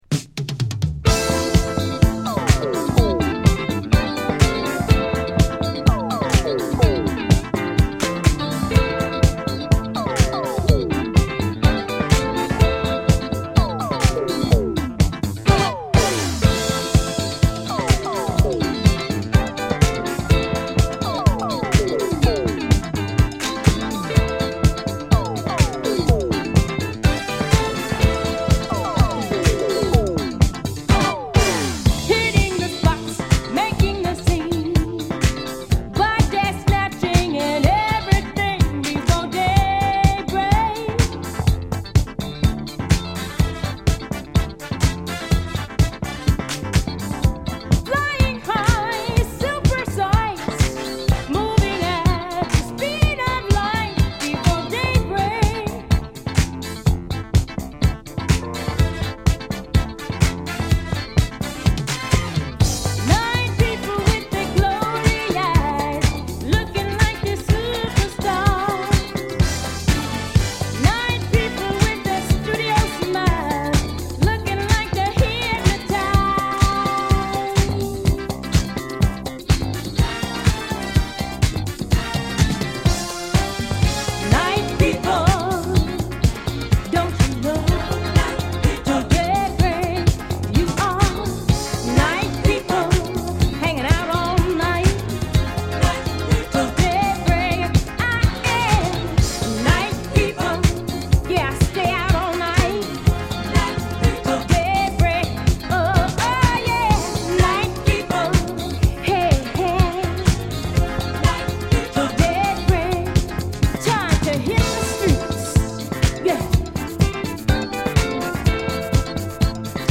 この時代らしいポップでキャッチーなディスコチューンが多めですが